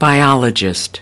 20. biologist /baɪˈɒlədʒɪst/: nhà sinh vật học